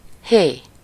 Ääntäminen
Ääntäminen Tuntematon aksentti: IPA: /ˈheː/ Haettu sana löytyi näillä lähdekielillä: unkari Käännös Ääninäyte Huudahdukset 1. hey US Esimerkit Dózsa György unokája vagyok én, Népért síró, bús, bocskoros nemes.